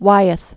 (wīĭth), Andrew Newell 1917-2009.